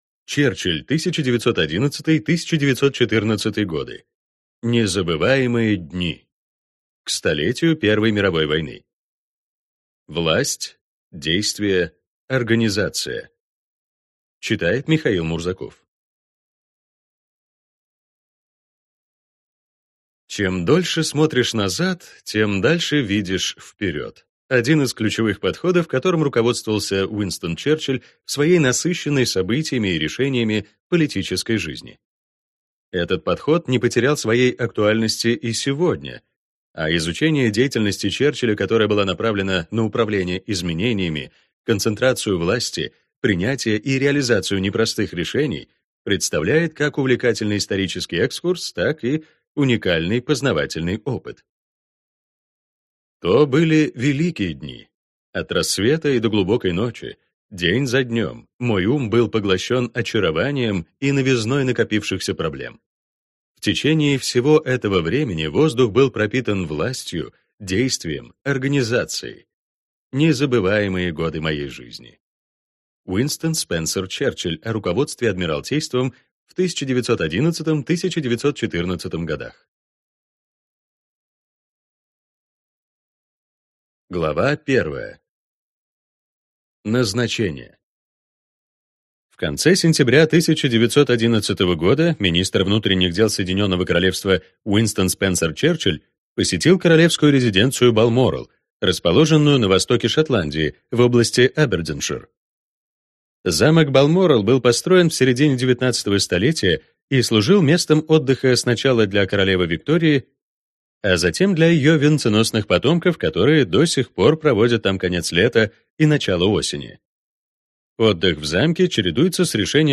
Аудиокнига Черчилль 1911–1914. Власть. Действие. Организация. Незабываемые дни | Библиотека аудиокниг